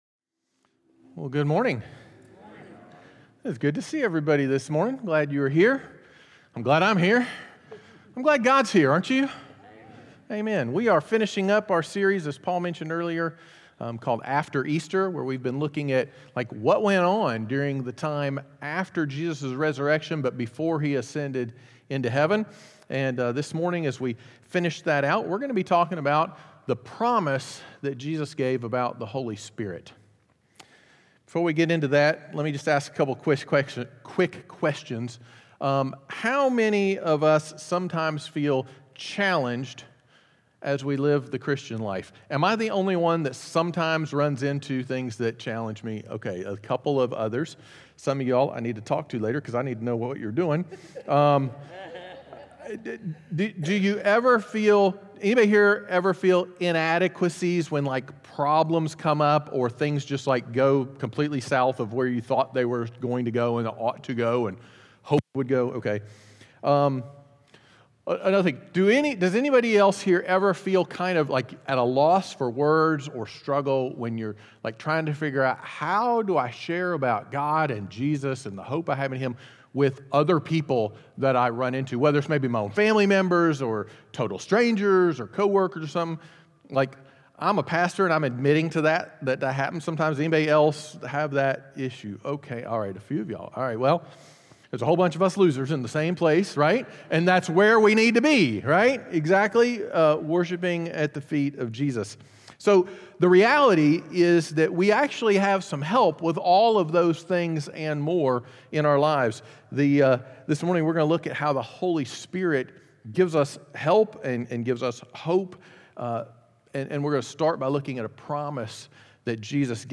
In this final sermon of the series